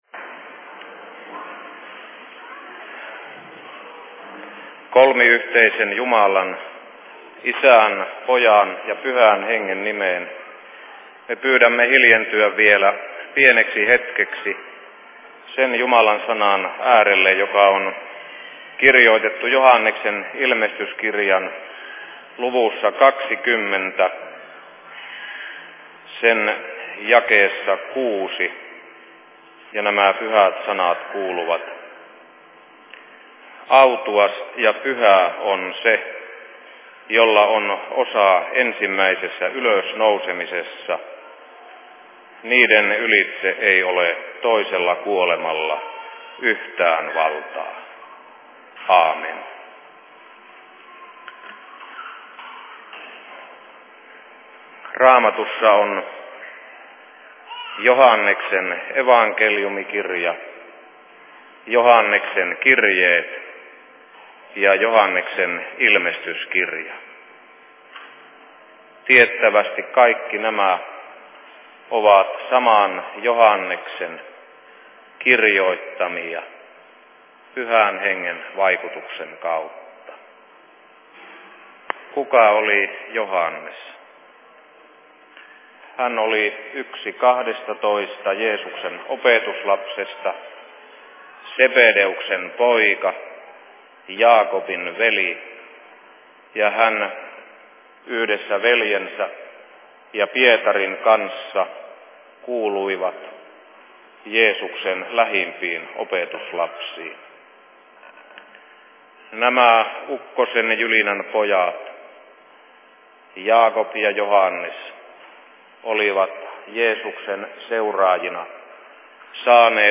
Kesäseurat/Seurapuhe 04.08.1991
Paikka: Rauhanyhdistys Helsinki